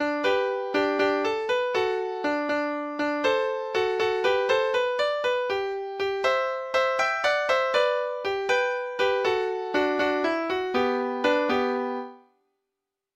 Lytt til data-generert lydfil
Den muntre melodien passar kan hende best til versa som er lagt i munnen på sisikken og spurven.